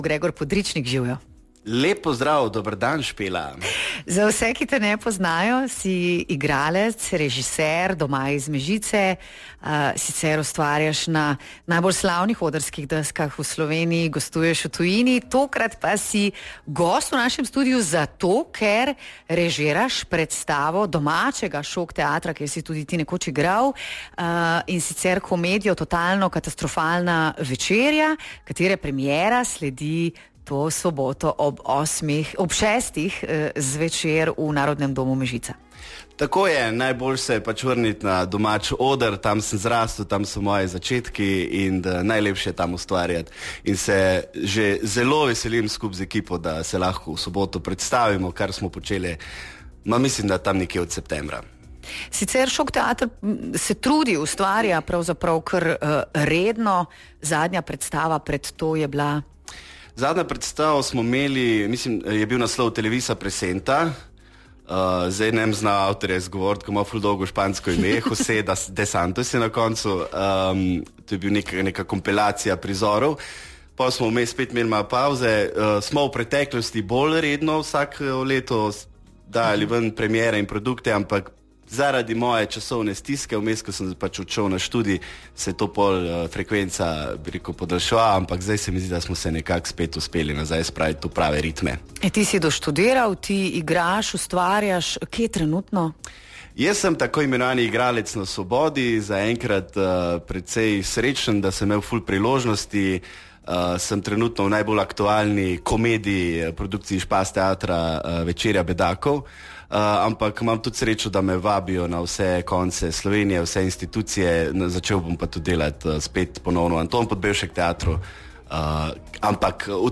pogovoru